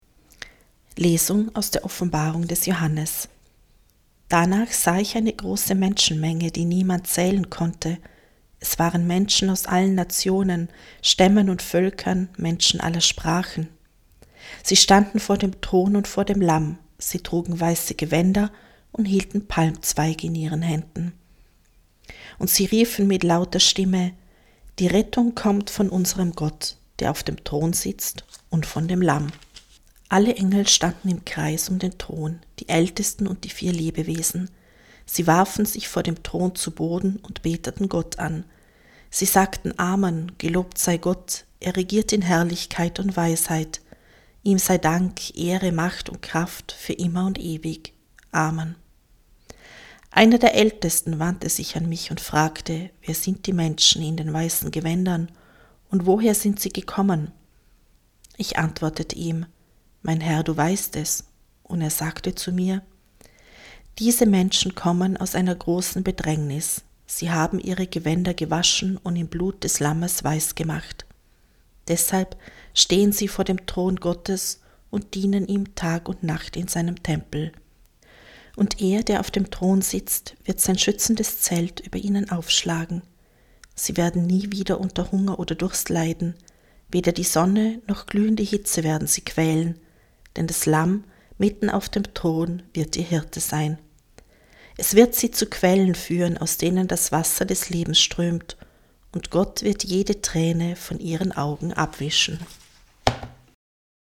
Wenn Sie den Text der 2. Lesung aus der Offenbarung des Johannes: